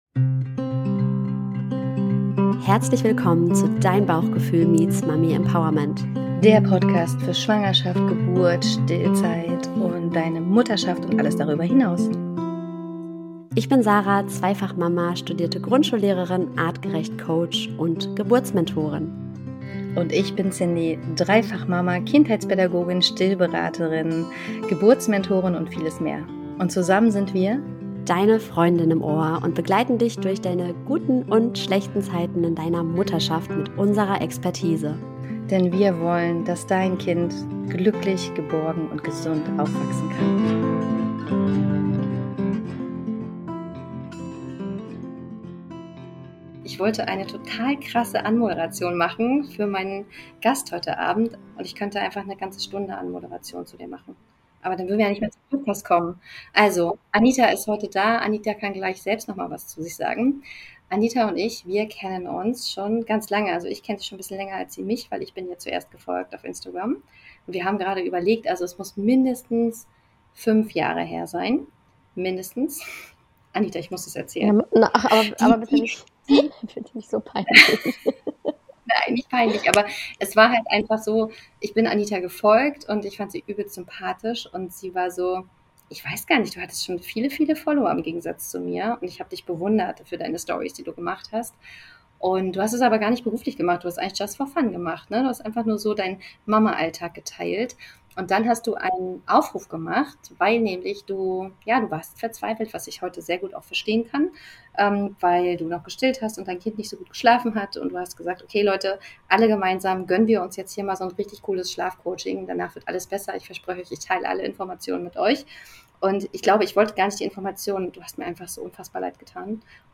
Einfach mal zuhören willst, wie zwei Mamas ehrlich über ihre Erfahrungen sprechen